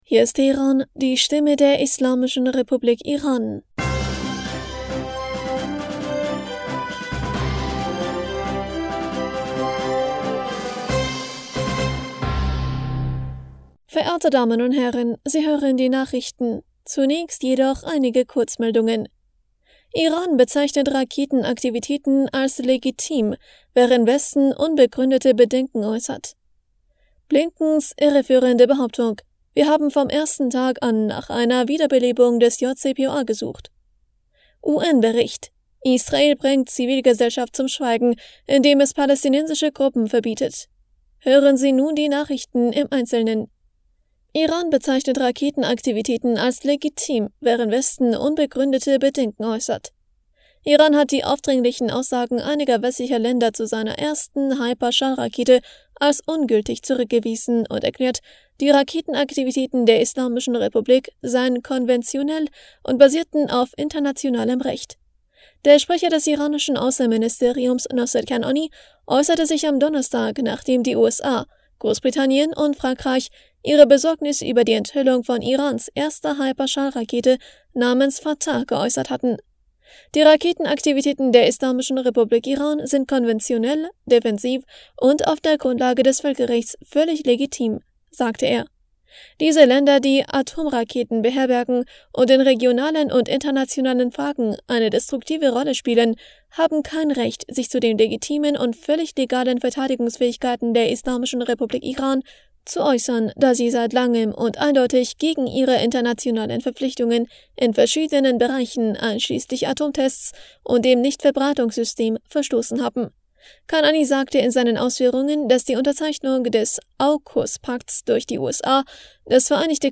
Nachrichten vom 09. Juni 2023